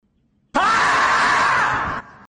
marmot.mp3